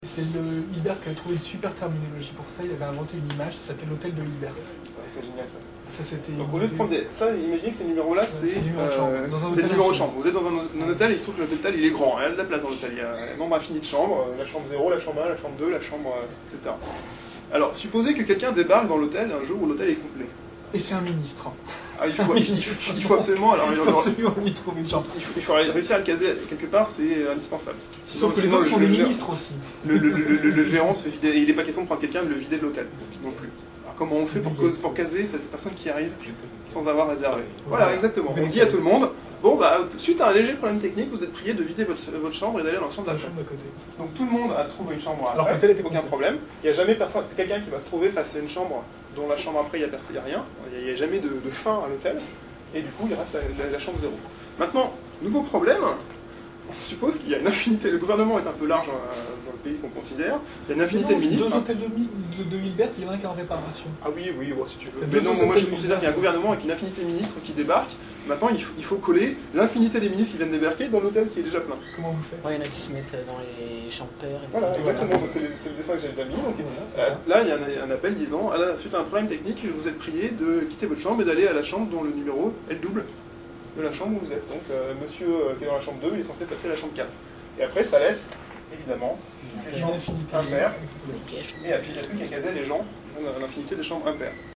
Interview Hôtel de Hilbert